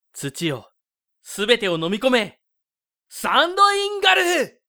ボイスサンプル@　　ボイスサンプルA